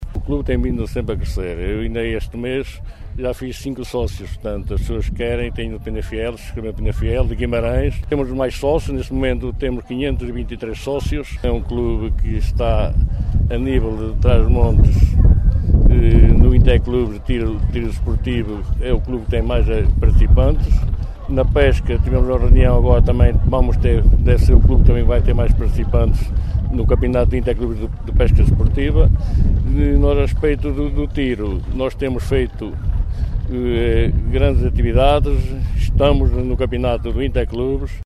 Declarações à margem das comemorações do 37º aniversário do Clube de Caça e Pesca de Macedo de Cavaleiros, onde a data foi marcada com uma missa campal, almoço convívio e ainda uma tarde com atividades desportivas.